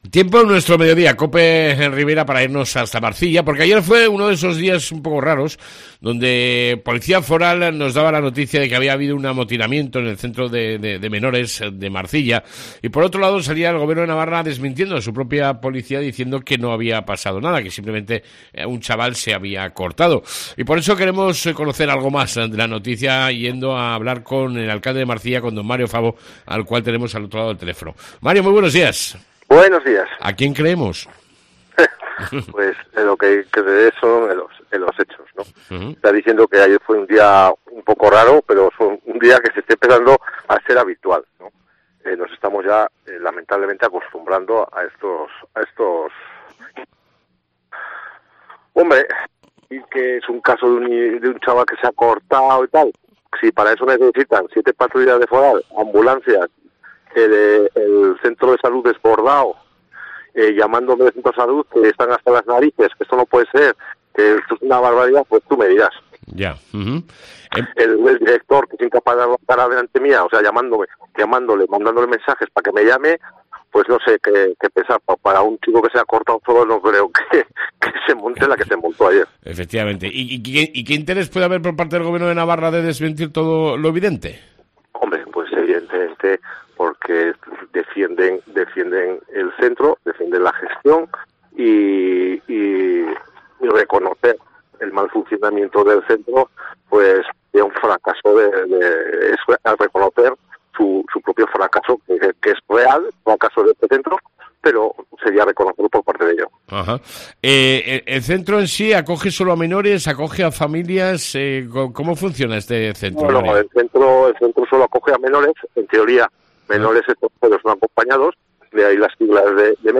El Alcalde Mario Fabo nos cuenta lo ocurrido ayer en Marcilla (Centro de acogida de menores)